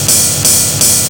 RIDE LOOP1-R.wav